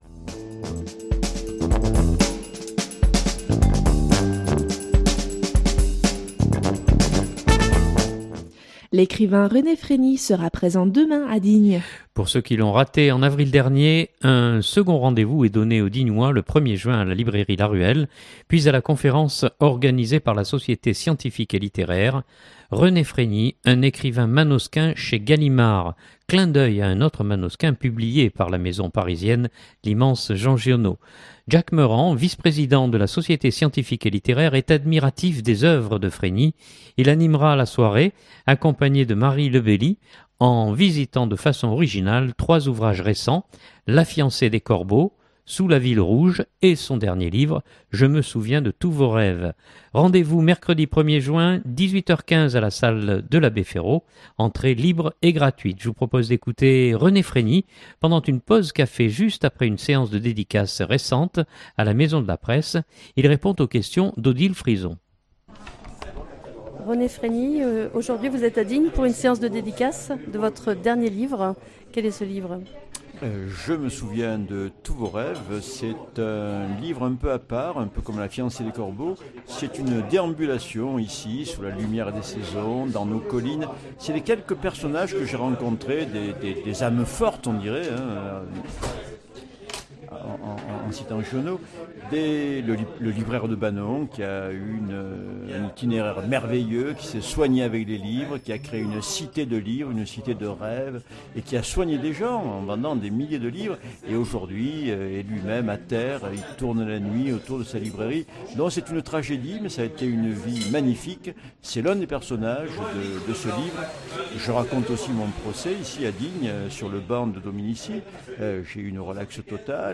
Je vous propose d’écouter René Frégni pendant une pause-café, juste après une séance de dédicace récente à la maison de la presse.